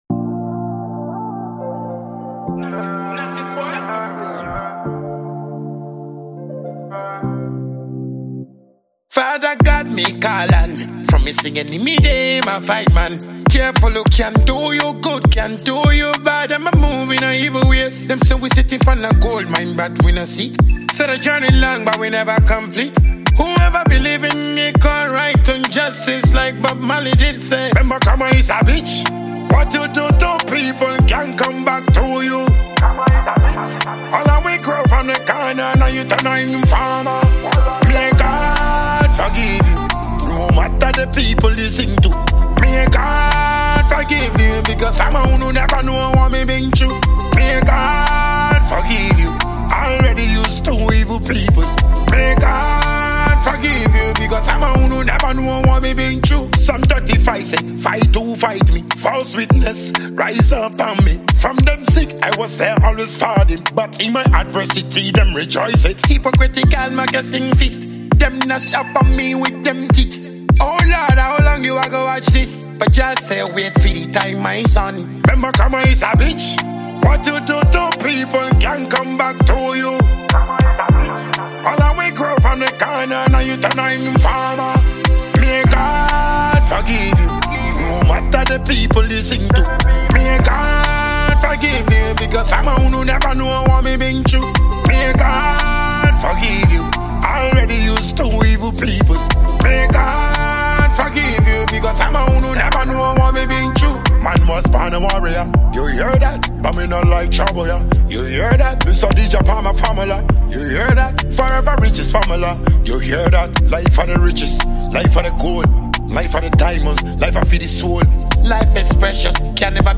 dancehall
blends infectious rhythms with thought-provoking lyrics